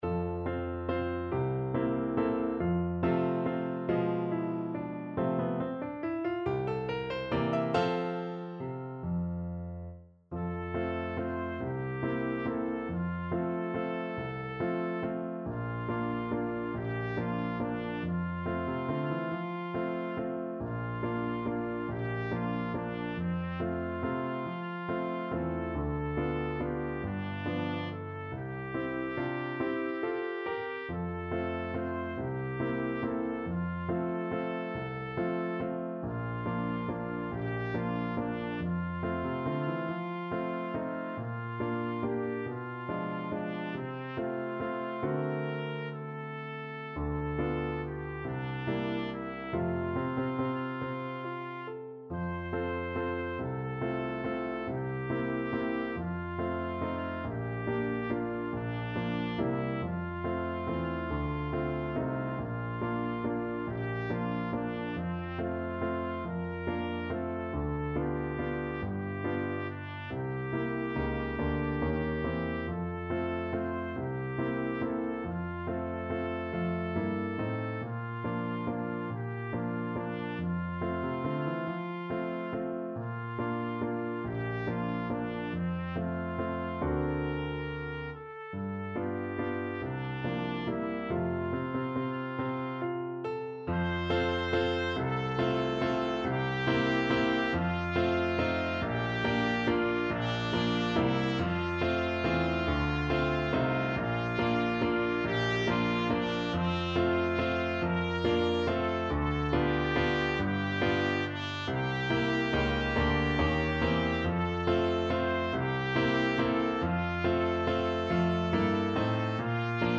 ~ = 140 Tempo di Valse
3/4 (View more 3/4 Music)
C5-C6
Pop (View more Pop Trumpet Music)